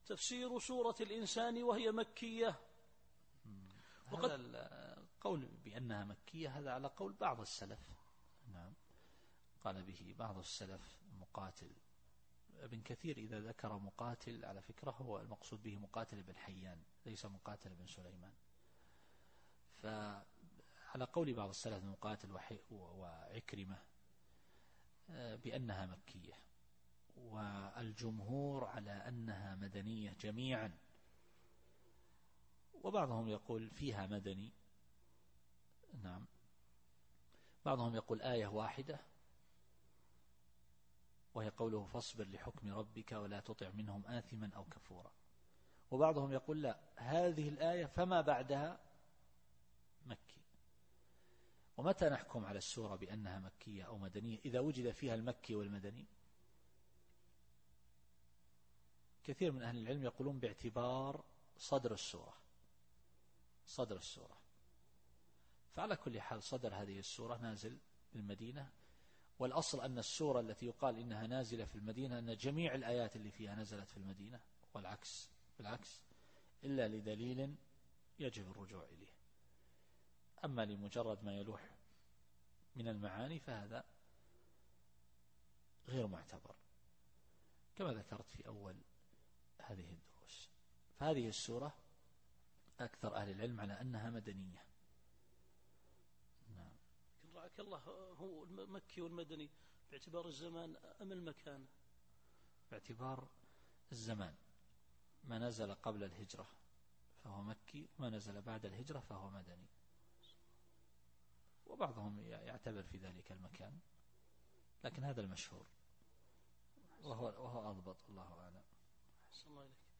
التفسير الصوتي [الإنسان / 1]